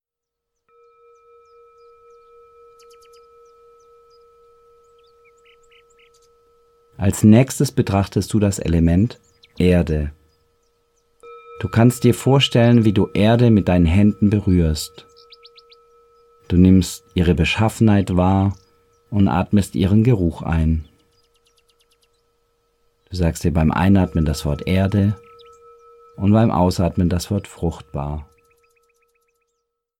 Einatmen – Ausatmen mit inneren Bildern –Die Meditationen zum Buch
Mit der visualisierten Atemmeditation geht das ganz leicht: Innere Bilder werden mit Wörtern und bewusstem Atmen kombiniert - und dafür genügen schon wenige Minuten. Ob entspannt auf dem Sofa, unterwegs in der Bahn oder beim Joggen: Mit den sechs geführten Meditationen erwecken Sie garantiert das Urlaubsgefühl in sich - auch mitten im Alltag.